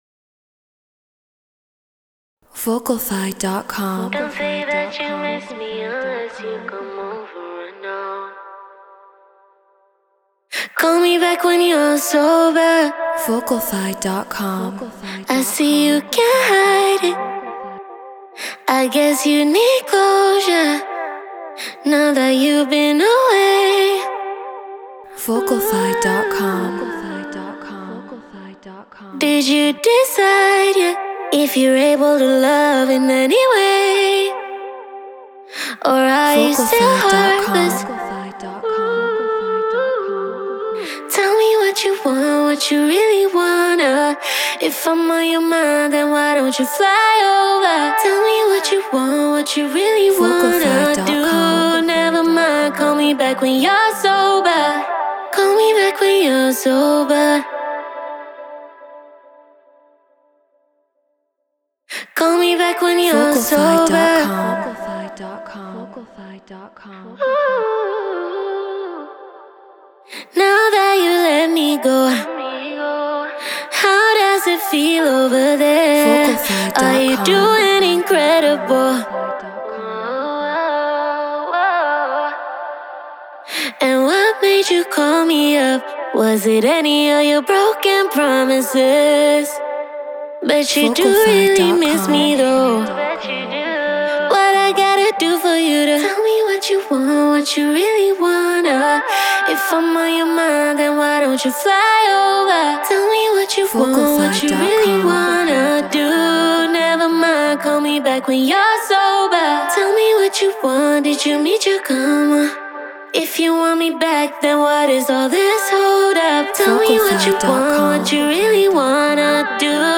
RnB 140 BPM Dmin
Shure SM7B Focusrite Scarlett Ableton Live Treated Room